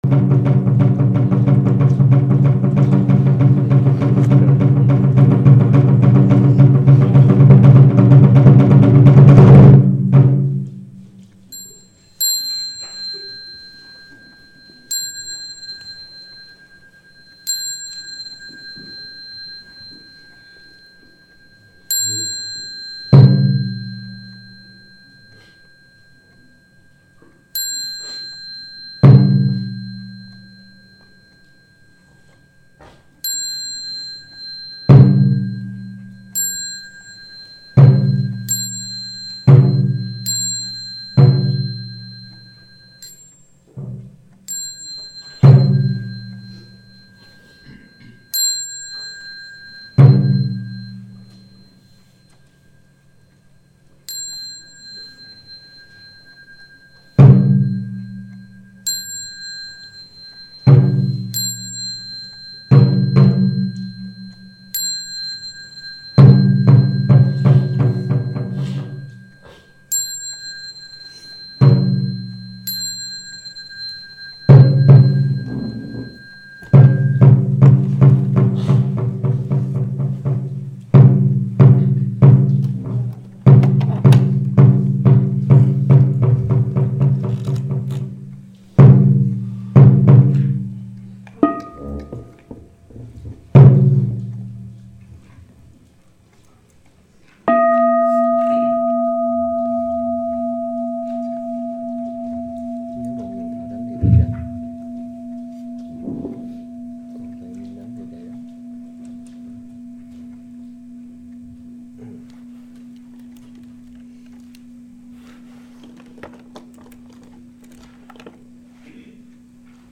B-01. TRỐNG HỘI CHUNG - KHAI LỄ
B-01. TRỐNG HỘI CHUNG - KHAI LỄ.mp3